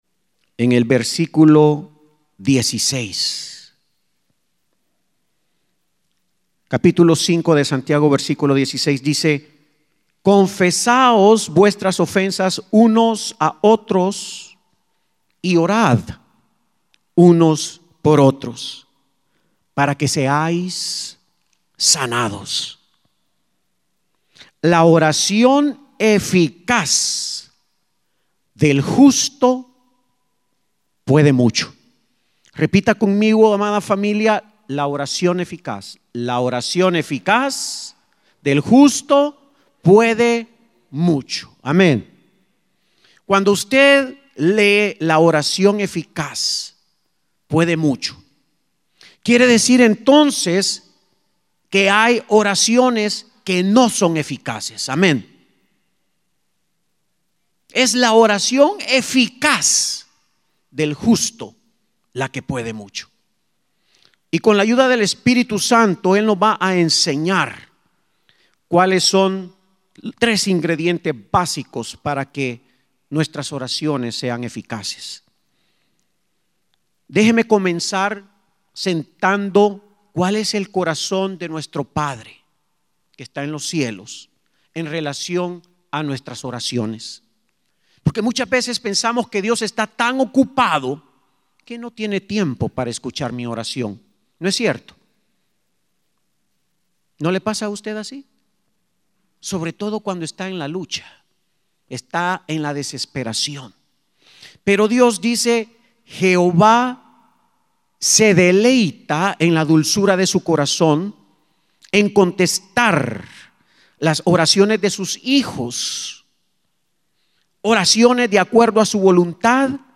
Service_1-26_14_La_oracion_eficaz.mp3